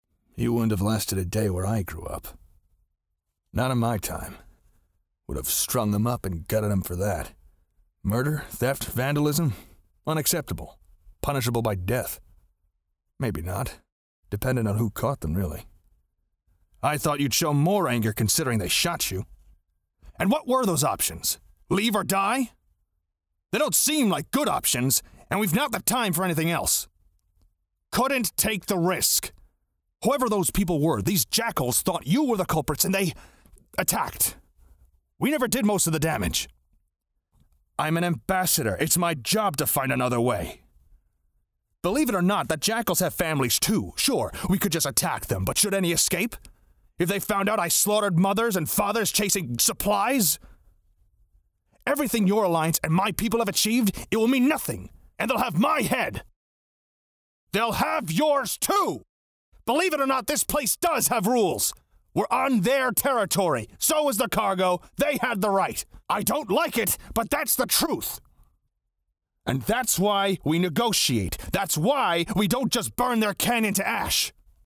Voice Actor
I want this person to sound like a man of authority, a soldier, power in his voice. He has to show a variety of emotions.
Ideal: Medium to medium low
Accent: North American
Simplifying this but he just has to sound like a badass soldier.